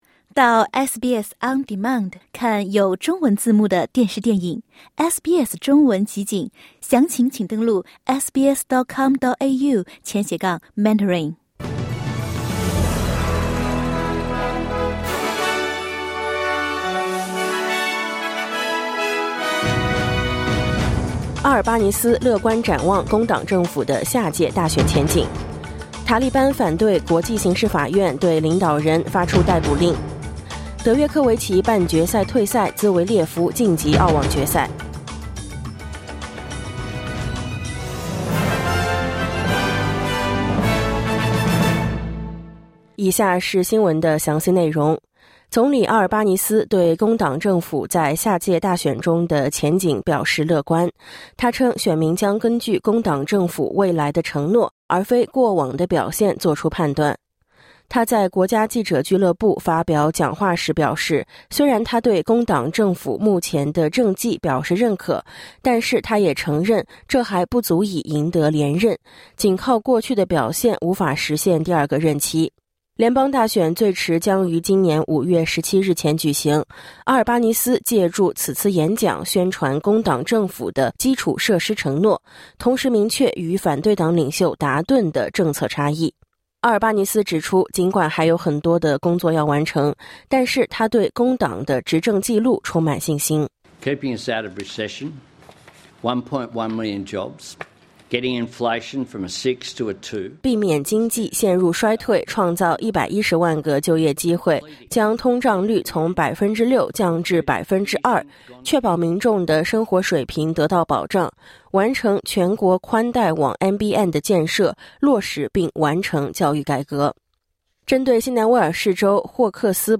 SBS早新闻（2025年1月25日）